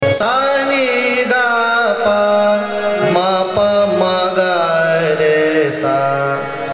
Raga
ThaatKafi
AvarohaS’- n D P, m- P m g- R S
Bhimpalasri (Avaroha)